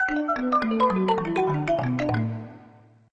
Index of /97/menu/sfx/
numbers_fly_out.ogg